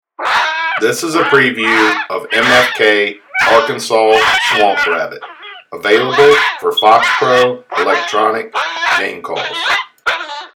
MFK Arkansas Swamp Rabbit – 16 bit
Recorded with the best professional grade audio equipment MFK strives to produce the highest